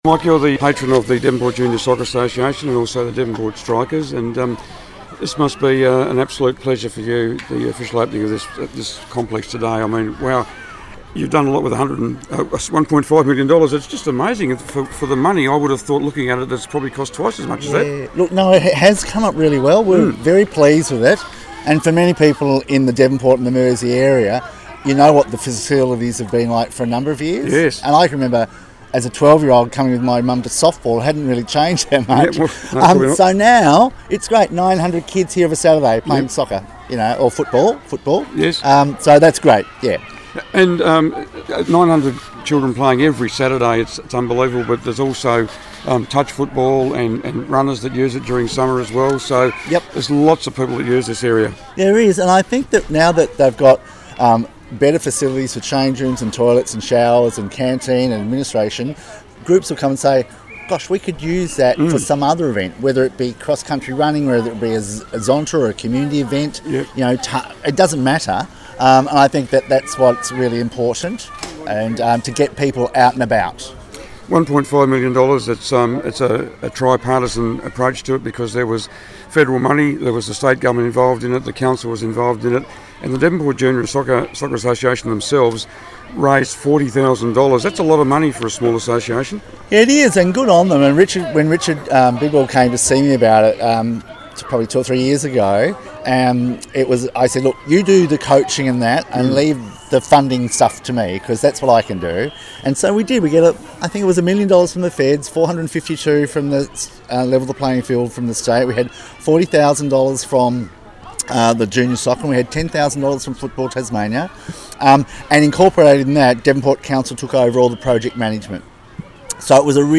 at the launch